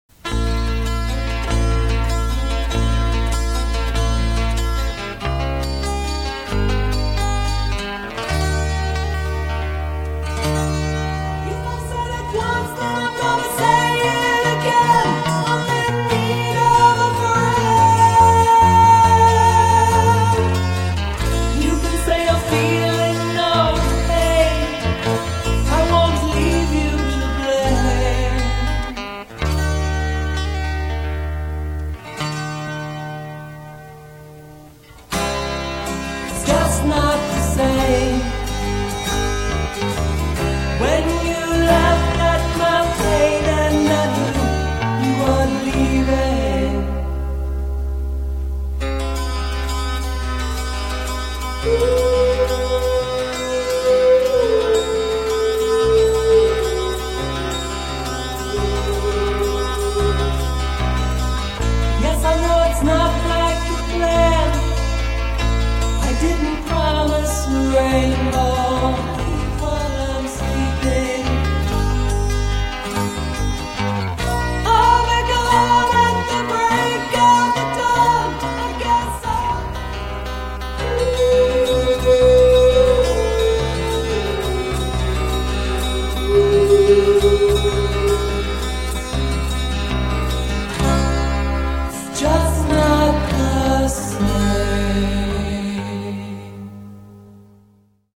Concept Symphonic Rock - 1.4 MB
All Electric & Acoustic 6 & 12-string guitars,
Vocals, Sound F/X, Co-Production